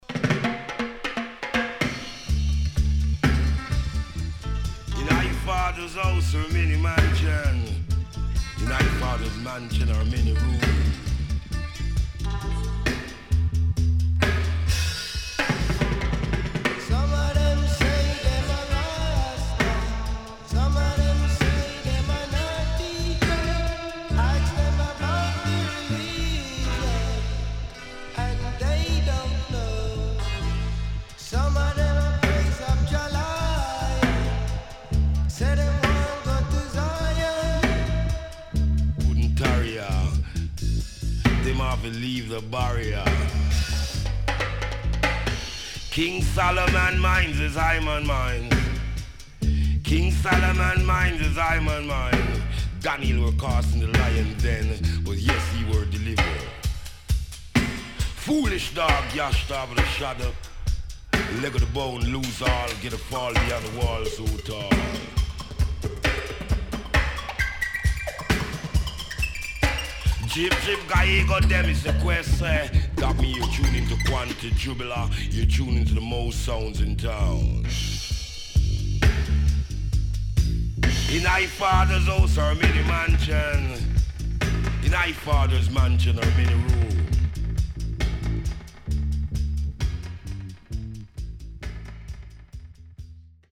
HOME > Back Order [VINTAGE LP]  >  KILLER & DEEP